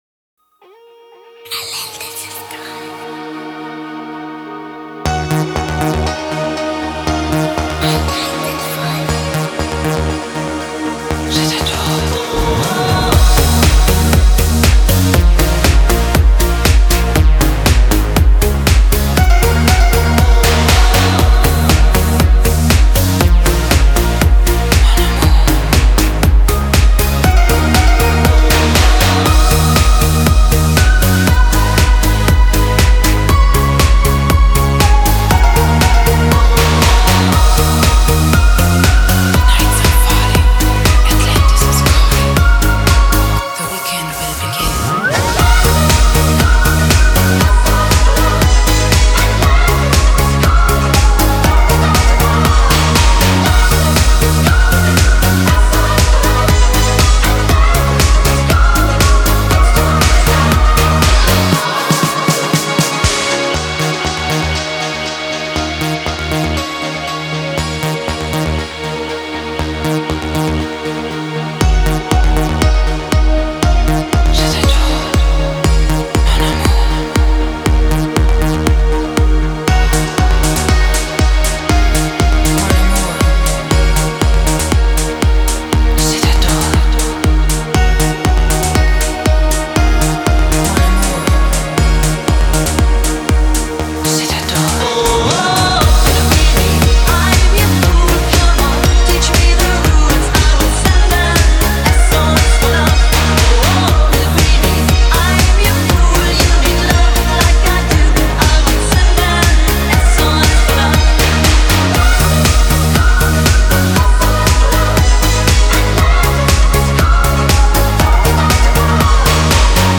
поп-песня в стиле Eurodisco